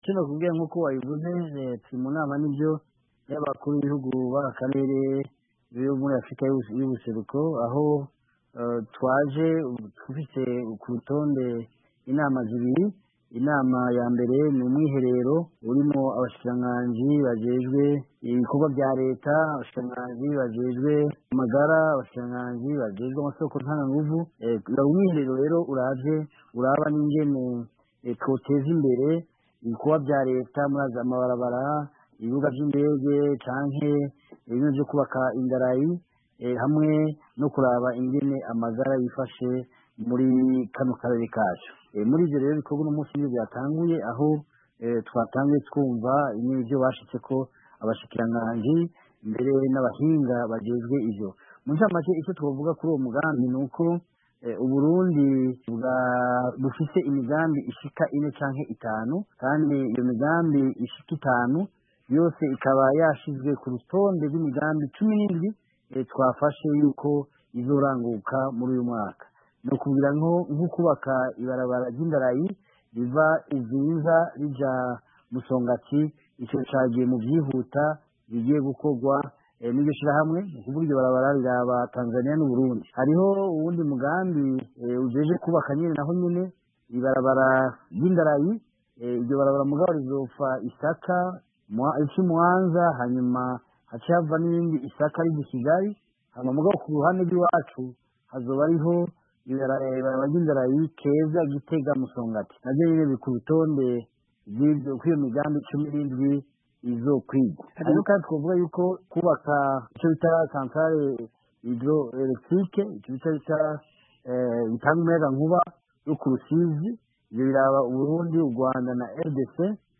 Icegera ca mbere c'umukuru w'igihugu w'Uburundi, Gaston Sindimwo, ni we yaserukiye Uburundi muri iyo nama.
Visi Prezida wa Mbere Gaston Sindimwo mu nama nkuru y'abakuru b'ibihugu bo mu karere ka EAC